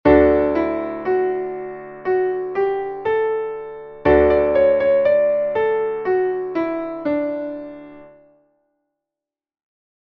Traditioneller Kanon